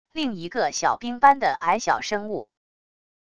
另一个小兵般的矮小生物wav音频